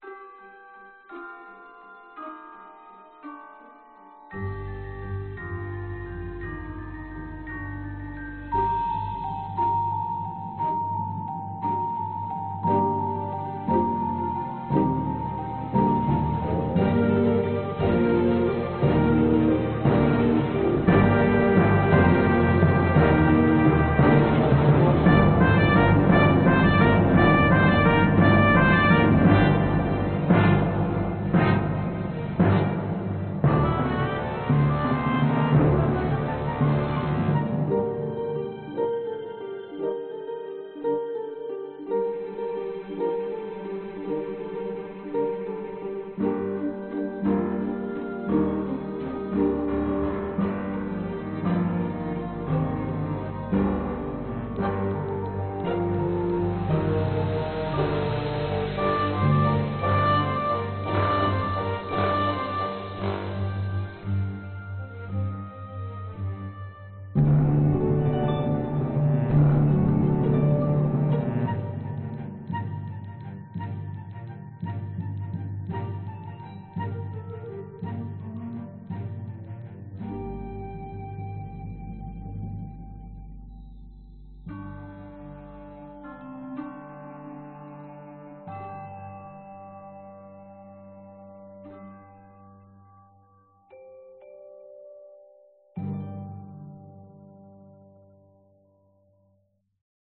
Tag: 圣诞 管弦乐 背景音乐 器乐